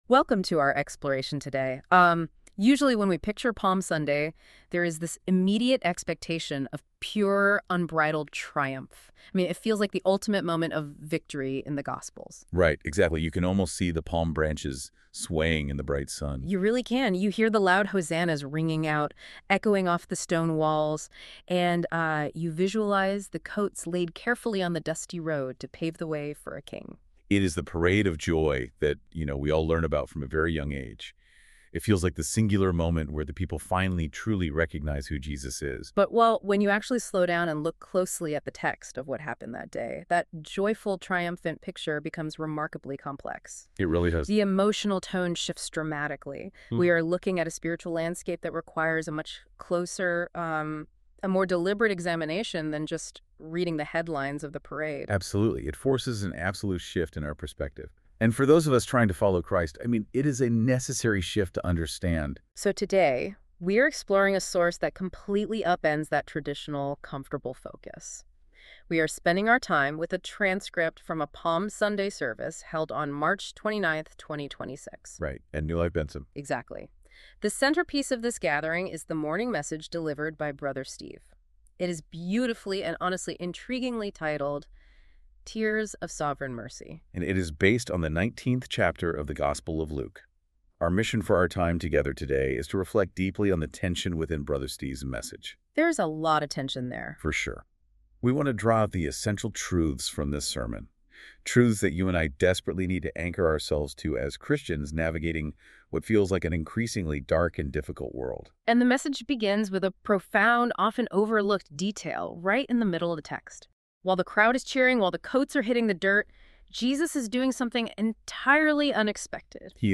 Sermons | NEW LIFE FAMILY WORSHIP CENTER
Palm Sunday 2026 Podcast.mp3